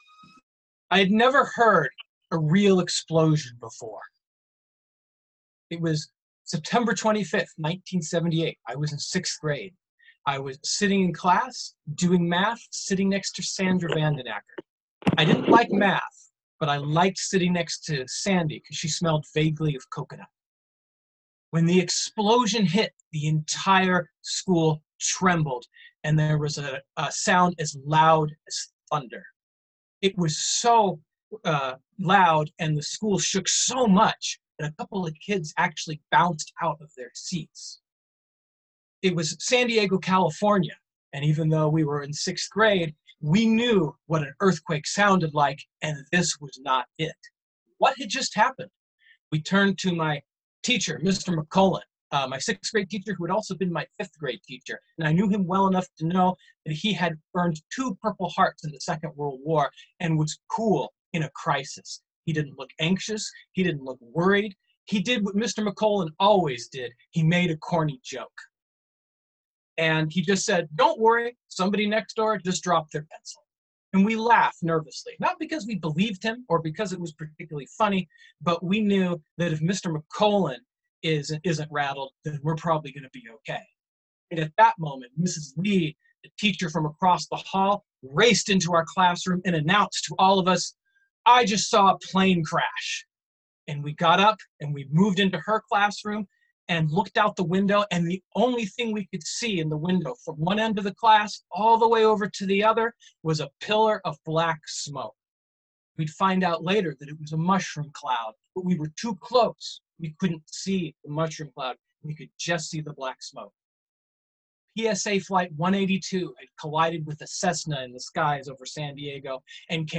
In the meantime, here are a few stories from our spring Zoom slams for your listening pleasure.